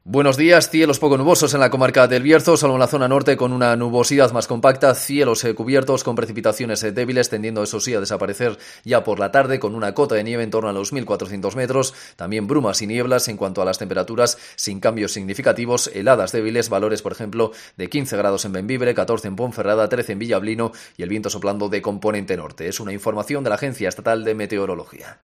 AUDIO: Previsión meteorológica para esta jornada de la mano de la Agencia Estatal de Meteorología (AEMET)